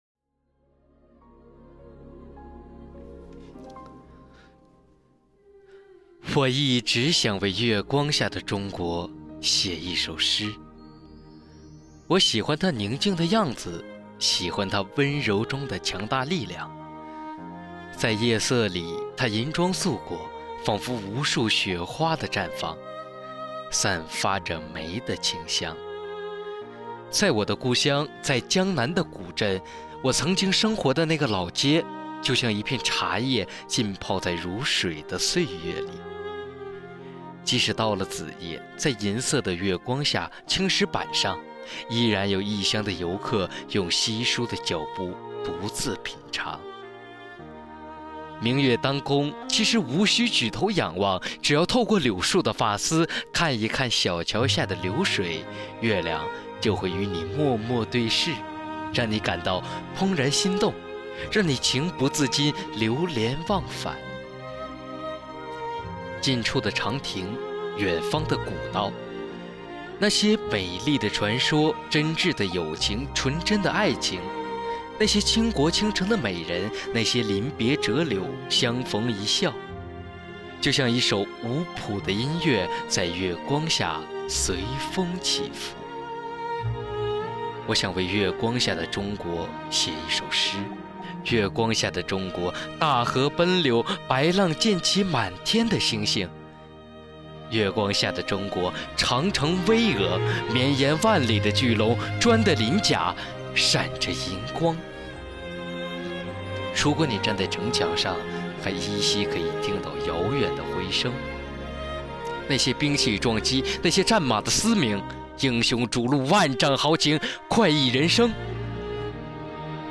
诵中华经典，品古韵流芳----中华经典诵读大赛圆满落幕